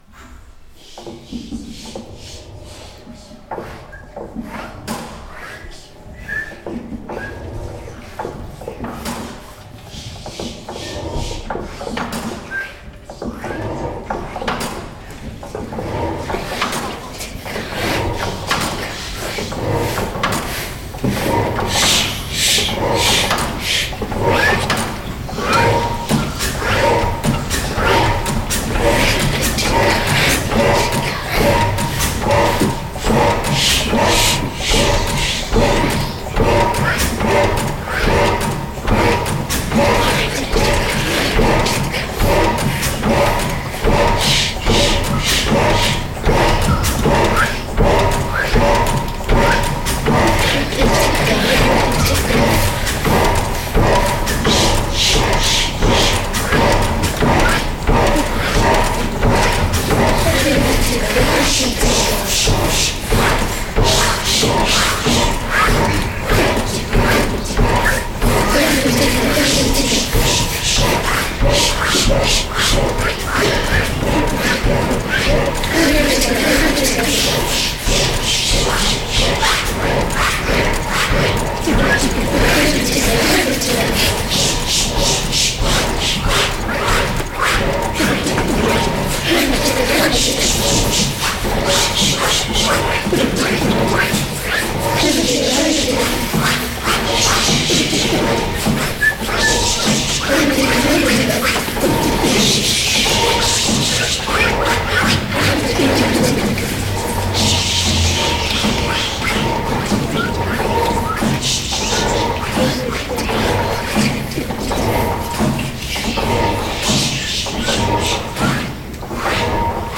The Gas Engine Room Sound Installation Thripaticka